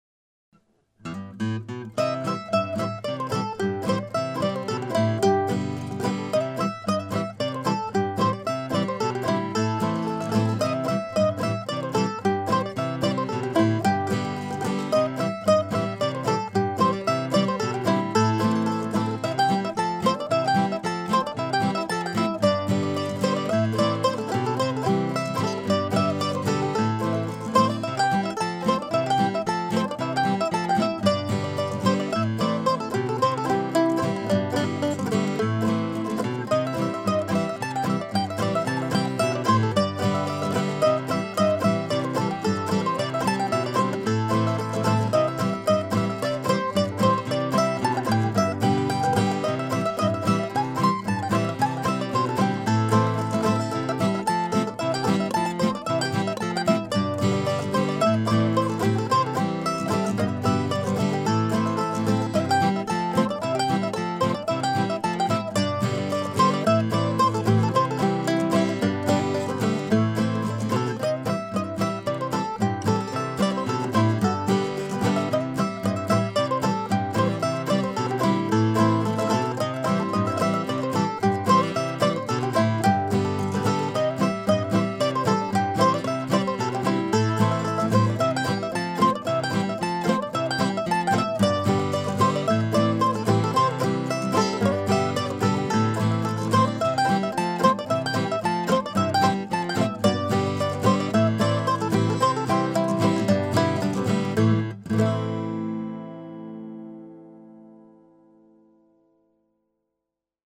Coulda Been a Log ( mp3 ) ( pdf ) A simple uptempo tune in C. Recorded a year or so ago, I just came across the recording again this past week.